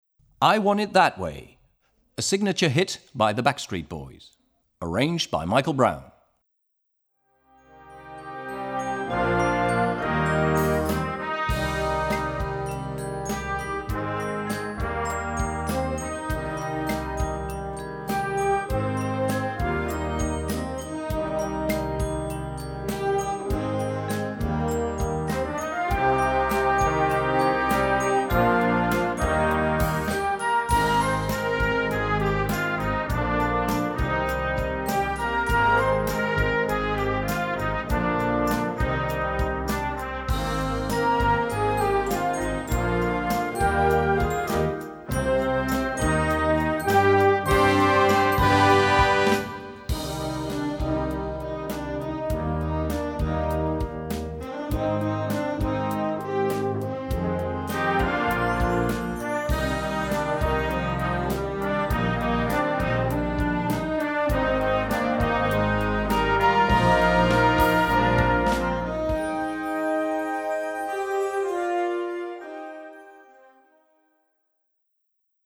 Gattung: Moderner Einzeltitel für Jugendblasorchester
Besetzung: Blasorchester